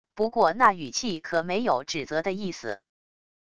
不过那语气可没有指责的意思wav音频生成系统WAV Audio Player